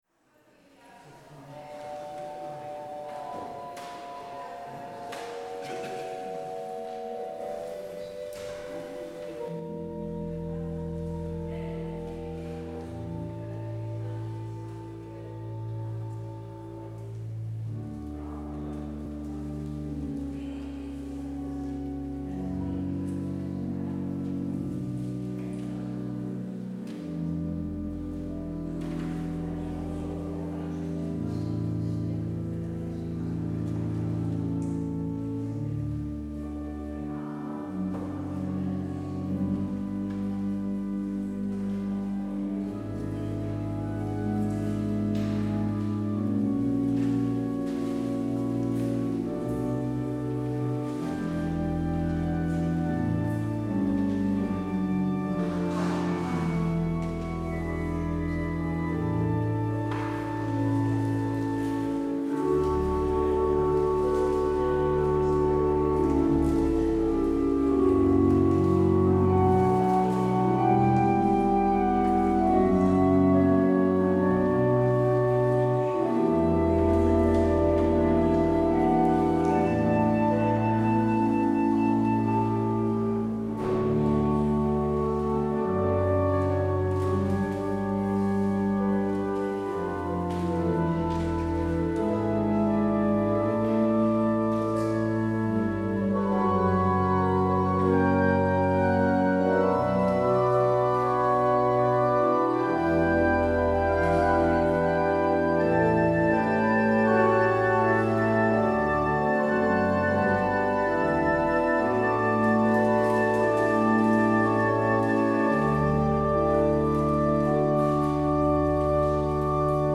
Luister deze kerkdienst terug:
Als openingslied hoort u: Heer, die mij ziet zoals ik ben – psalm 139, vers 1 en 2. Het slotlied is: Zo vriendelijk en veilig als het licht (Nieuwe Liedboek 221).